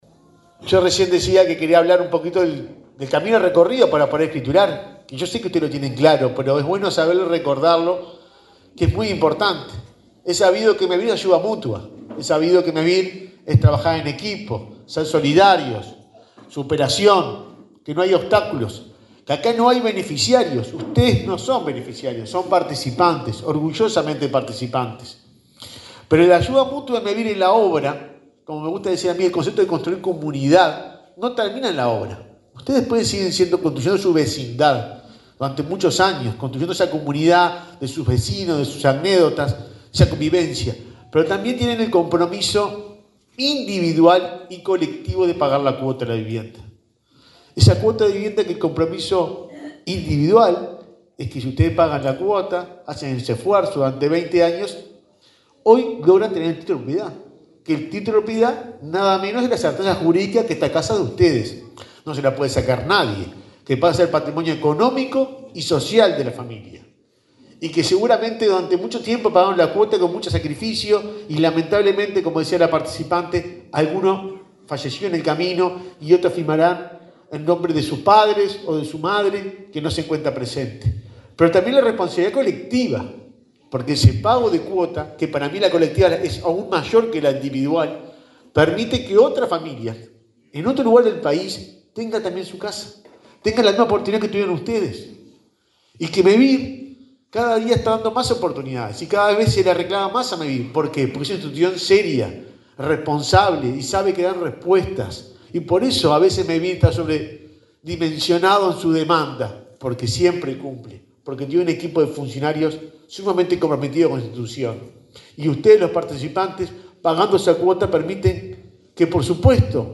Palabras del presidente de Mevir, Juan Pablo Delgado
El presidente de Mevir, Juan Pablo Delgado, encabezó el acto de escrituración de viviendas en la localidad de Cufré.